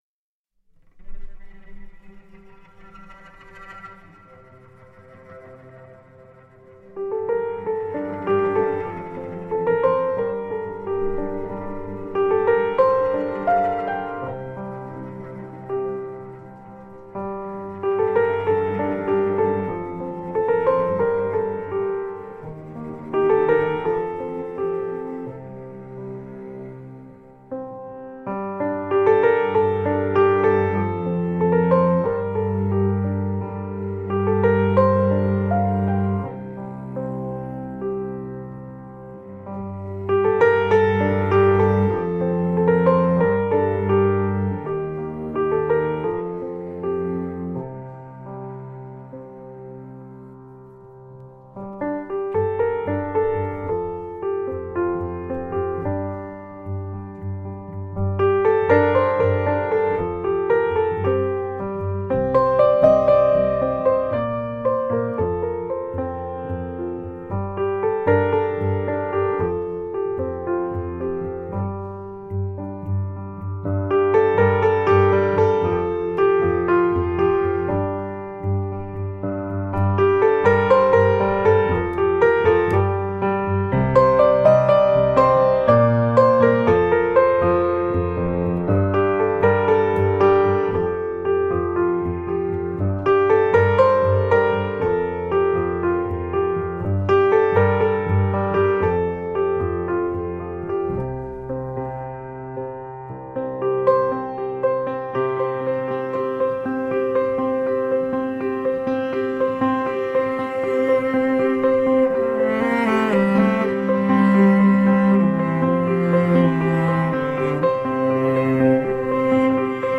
Intriguing piano compositions with dynamic accompaniment.
Tagged as: New Age, Folk, Instrumental